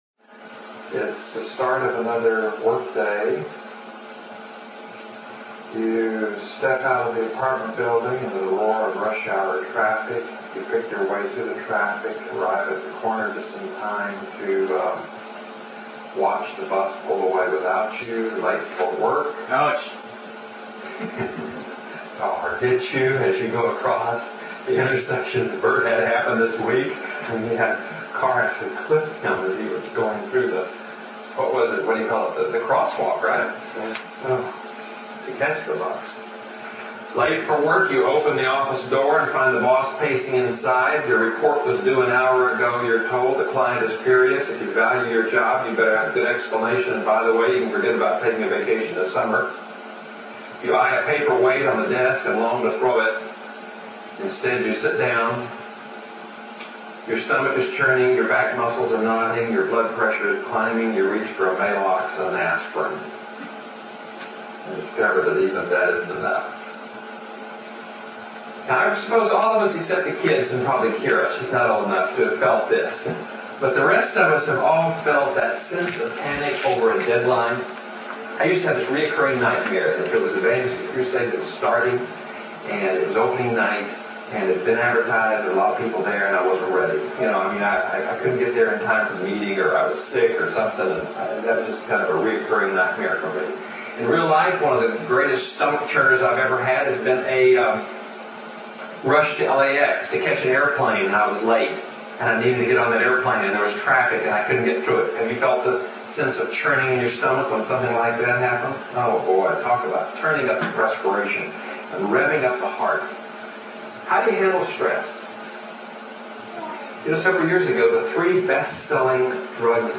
Online Sermon How to Handle Stress